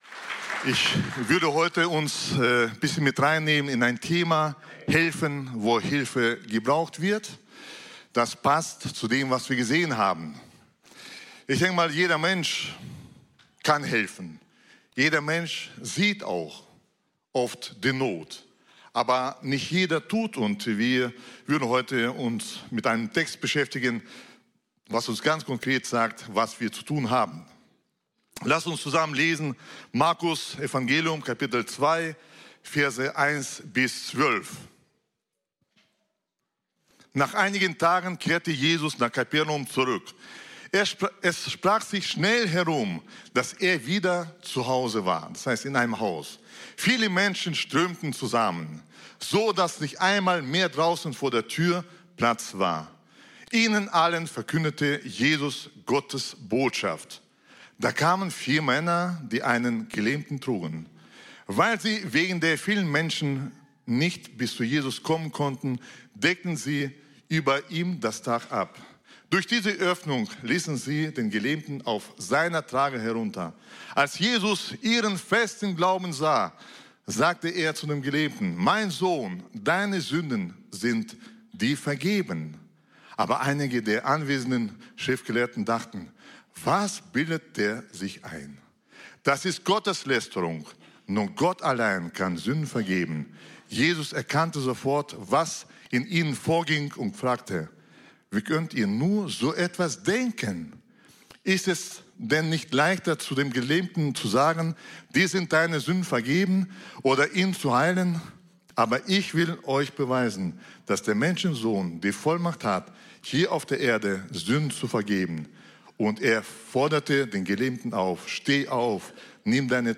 Predigten | Seite 3 | Freie Christengemeinde Bielefeld